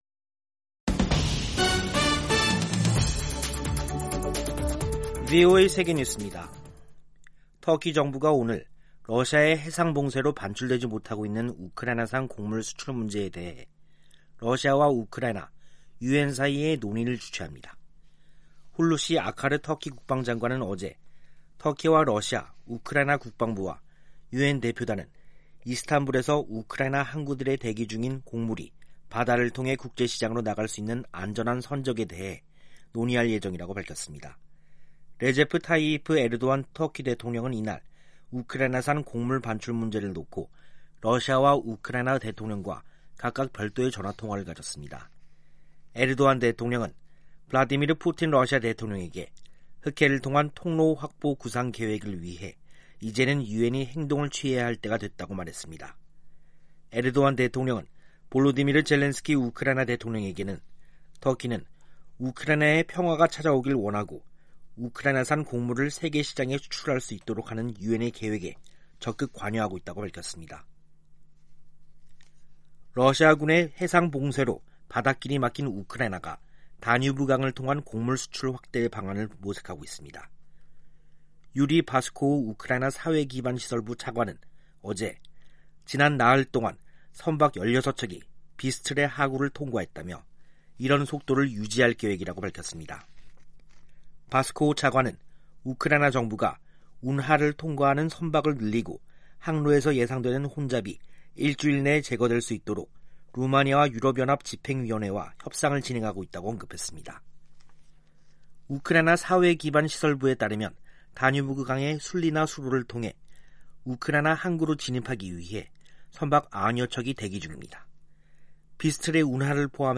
세계 뉴스와 함께 미국의 모든 것을 소개하는 '생방송 여기는 워싱턴입니다', 2022년 7월 13일 저녁 방송입니다. '지구촌 오늘'에서는 우크라이나 곡물 운송에 관한 4자 협의 진행 소식, '아메리카 나우'에서는 의사당 난입 사건 책임이 도널드 전 트럼프 대통령에게 있다고 하원 특위가 지적한 이야기 전해드립니다.